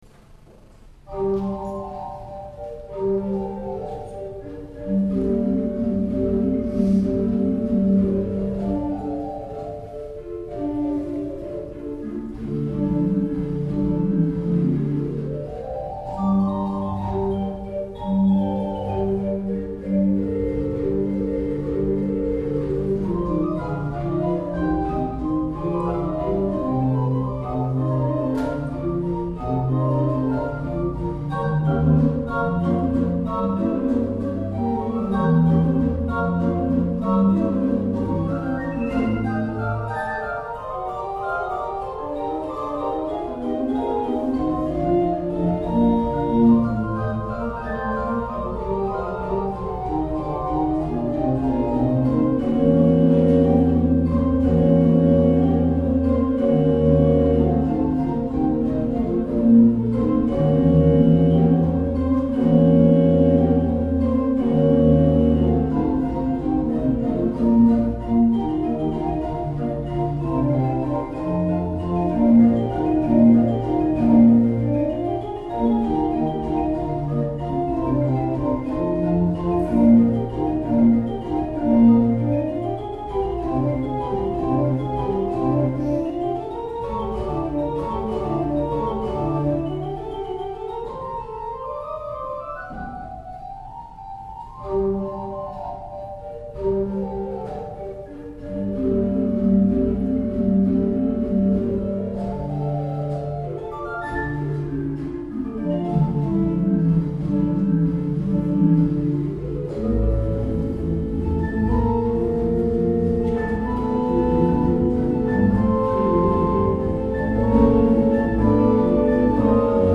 Saltvik